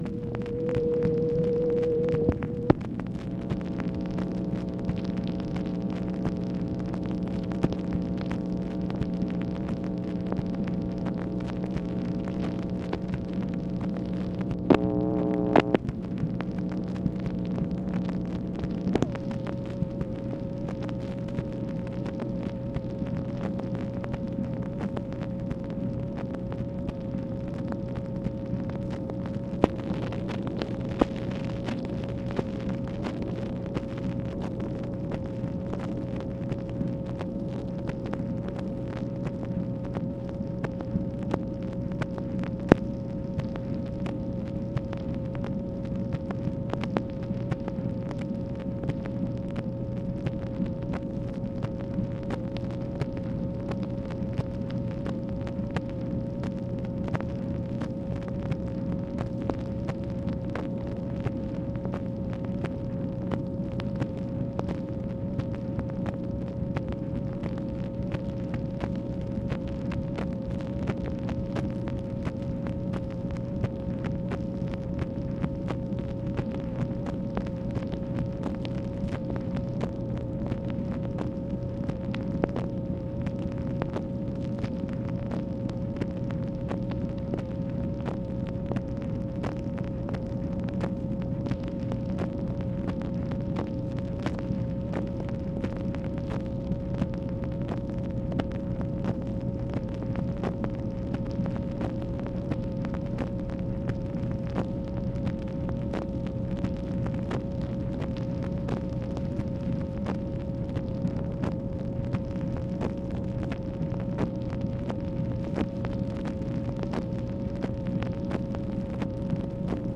MACHINE NOISE, October 23, 1964
Secret White House Tapes